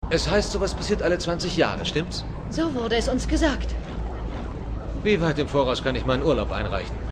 Sheppard spricht mit Doktor Weir über den großen Sturm alle 20 Jahre.